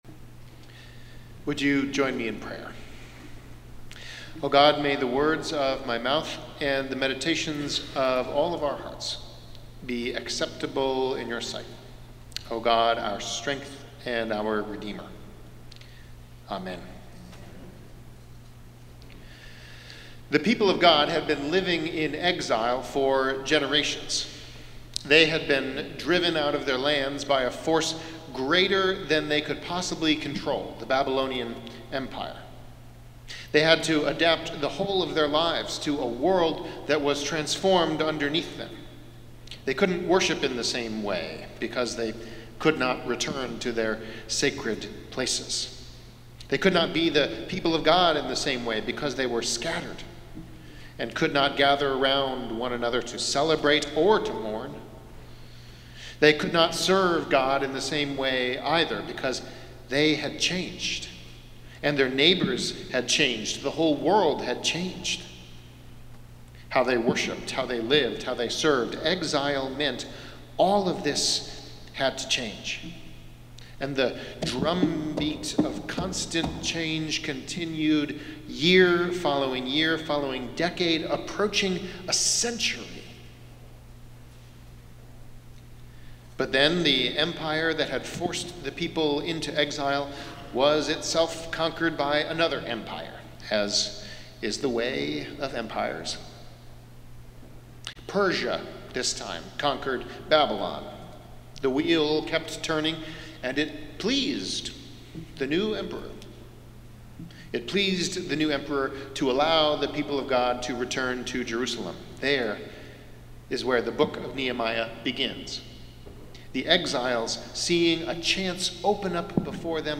sermon audio_33.mp3